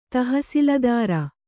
♪ tahasiladāra